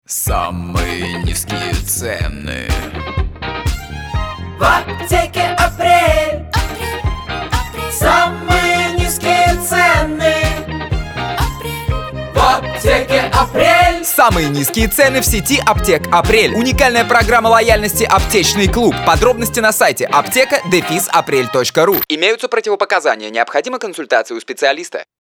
Рекламная песня
2 вариант концовки мужским и женским голосом